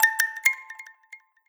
Index of /phonetones/unzipped/Lenovo/A6000/notifications
Bump.ogg